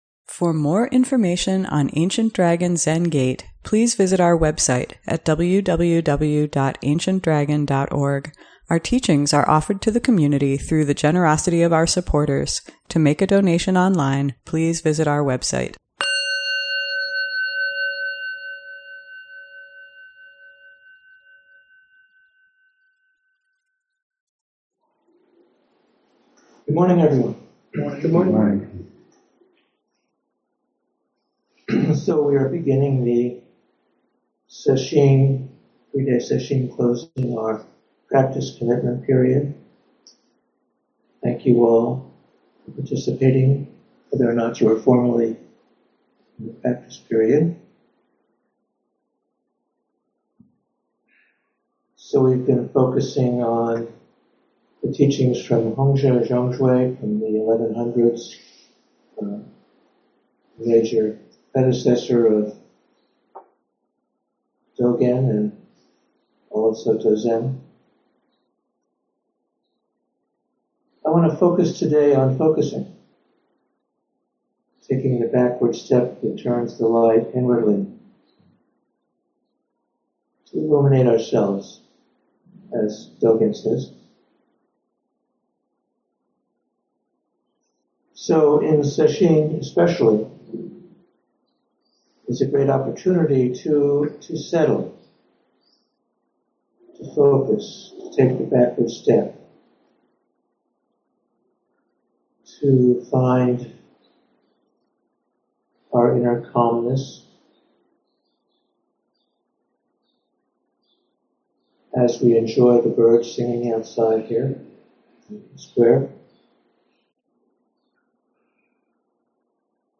ADZG Friday Morning Dharma Talk